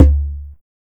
20 CONGA.wav